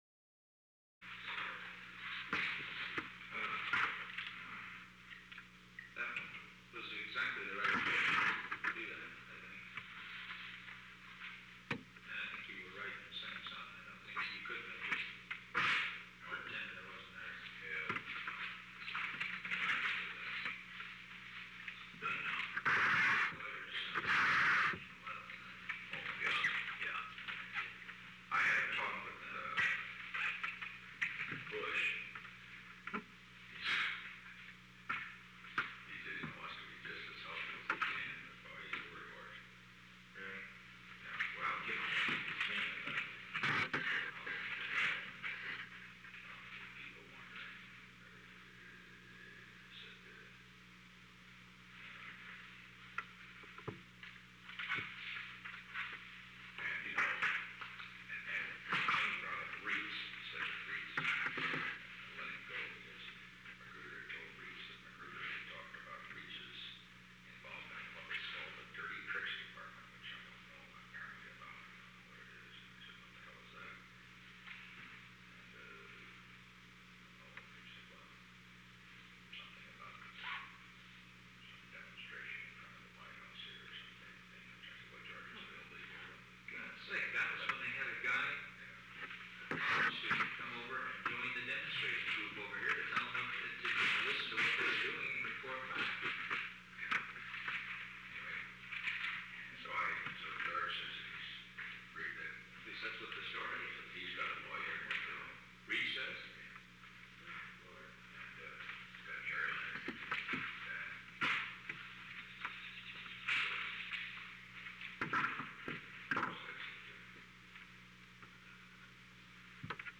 Conversation No. 903-6 Date: April 20, 1973 Time: 11:07 am - 11:23 am Location: Oval Office The President met with H. R. (“Bob”) Haldeman.